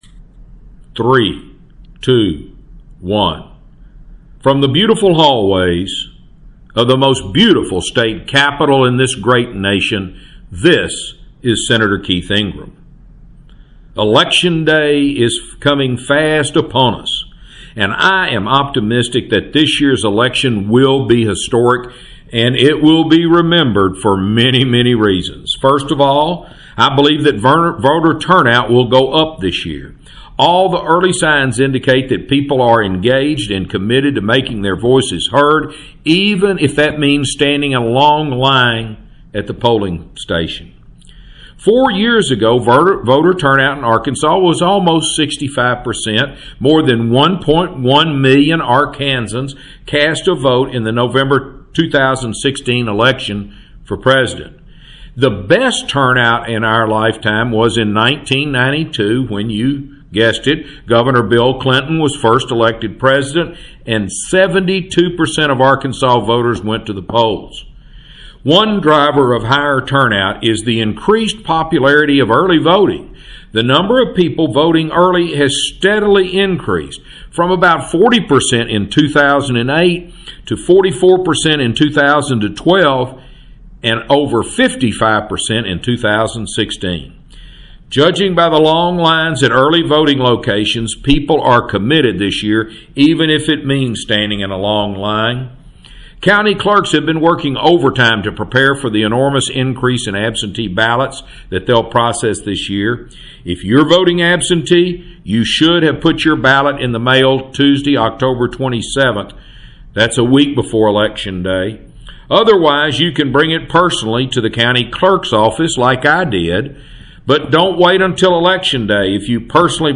Weekly Address – October 30, 2020 | 2020-10-29T16:17:27.755Z | Sen.